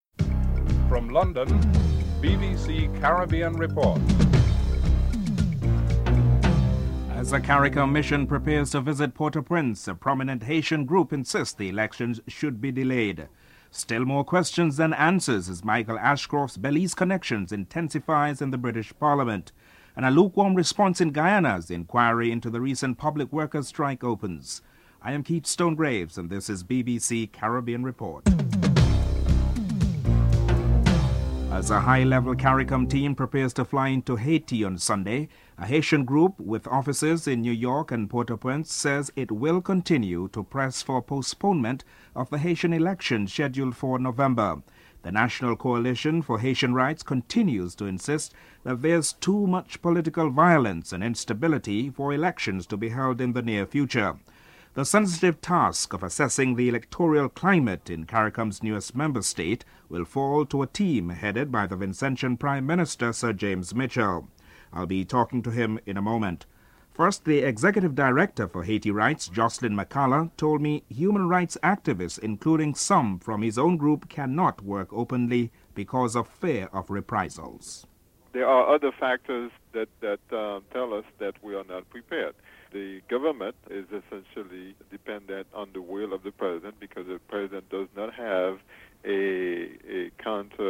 1. Headlines with anchor
Sir James Mitchell discusses the role of the CARICOM mission (00:31 – 05:18)